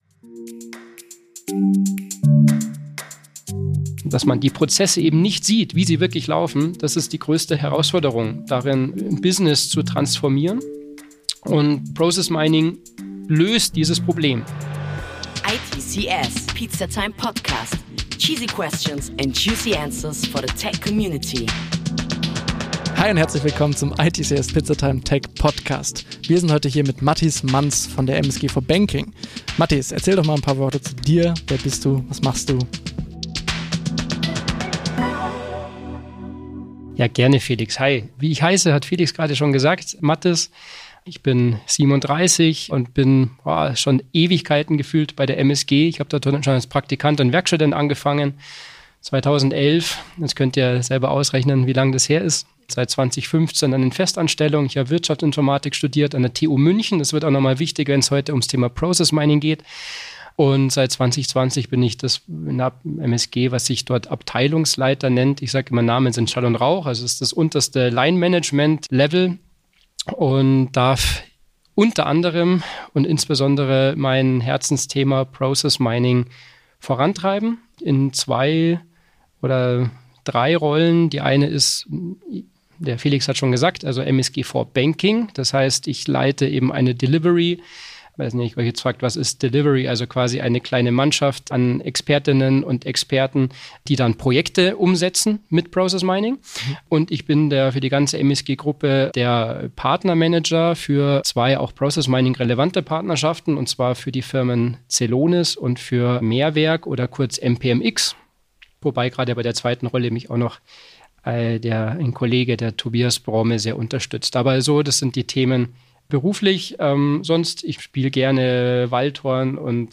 ITCS Pizzatime steht für Snackable Content mit authentischen Einblicken in die Tech- und IT-Branche und das alle 2 Wochen frisch serviert! Seid dabei und lasst Euch inspirieren, wenn wir Brancheninsider aus den verschiedensten Bereichen zu den aktuellsten und spannendsten Tech- und IT-Trends befragen.